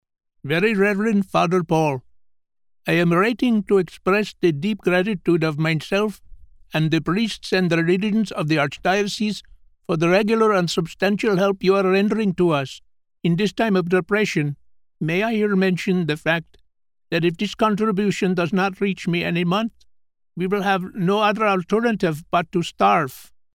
Mature Adult
Has Own Studio
Indian